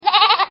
BabyGoat1.mp3